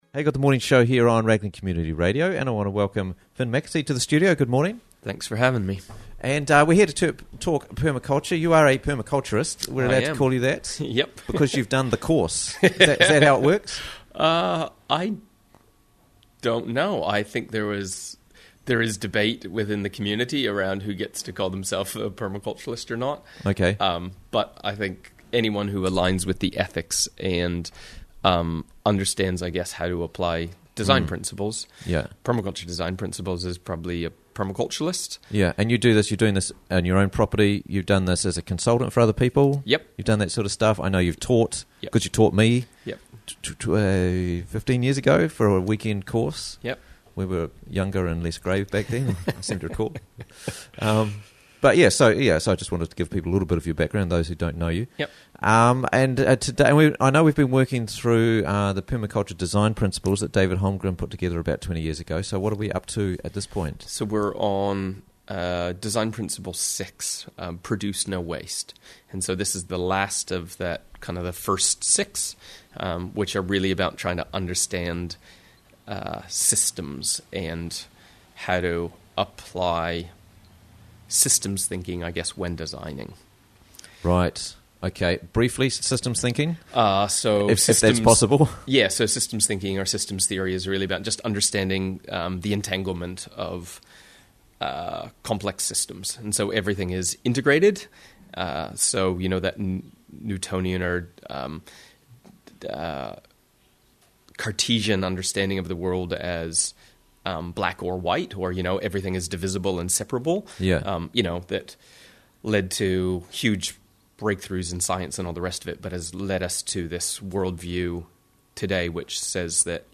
is in studio to look at this Permaculture Design Principle.